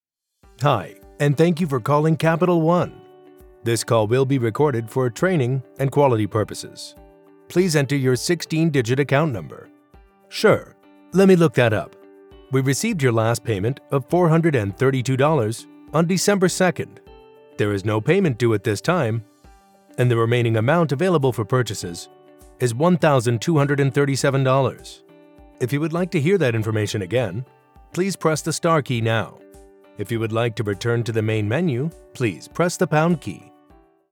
Englisch (Amerikanisch)
Tief, Natürlich, Unverwechselbar, Vielseitig, Warm
Telefonie